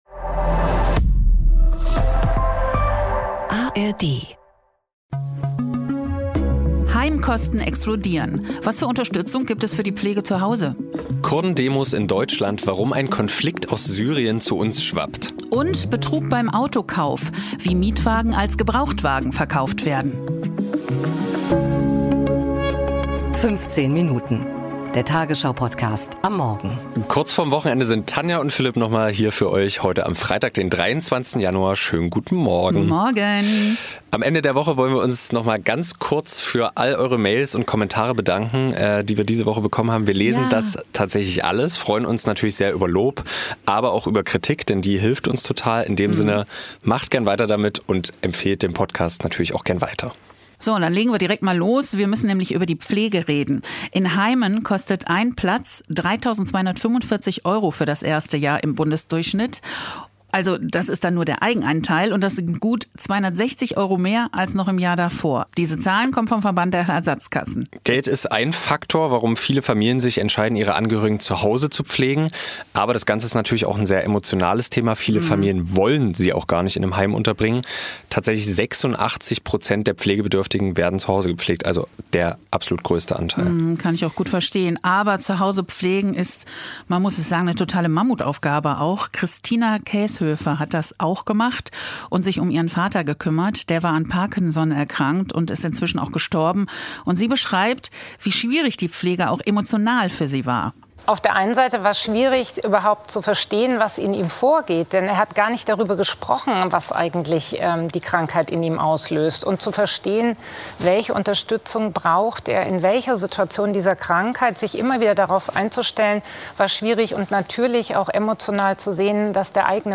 Der tagesschau-Podcast am Morgen Author: tagesschau Language: de Genres: Daily News , News Contact email: Get it Feed URL: Get it iTunes ID: Get it Get all podcast data Listen Now... Teure Pflege / Kurden-Demos wegen Syrien / Betrug beim Gebrauchtwagenkauf